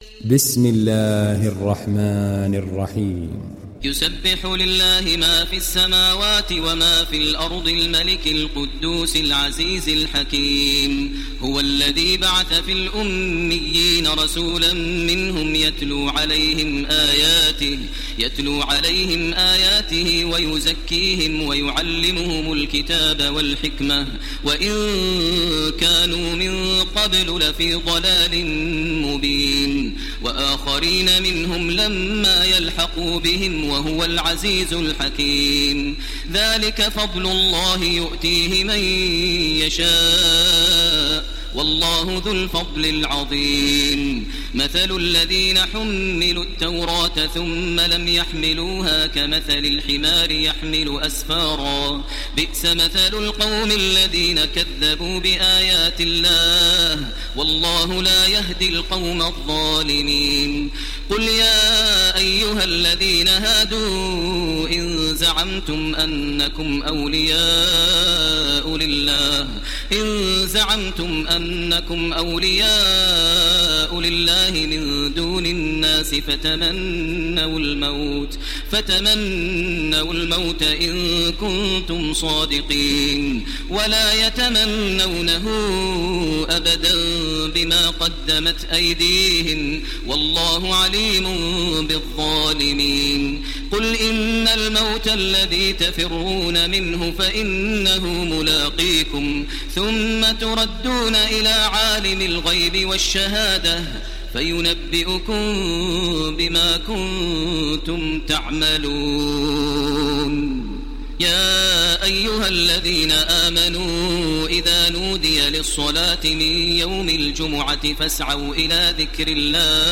ডাউনলোড সূরা আল-জুমু‘আ Taraweeh Makkah 1430